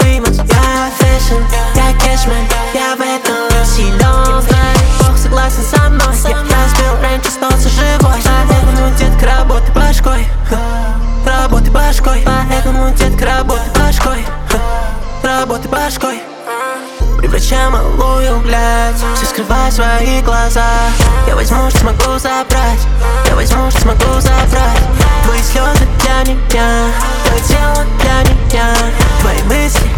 пробивного бита и лаконичного сэмпла
Жанр: Хип-Хоп / Рэп